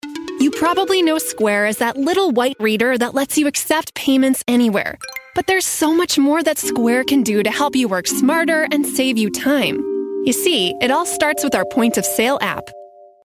Corporate Narration
• Enthusiastic, Authentic, Informative - Square Financial App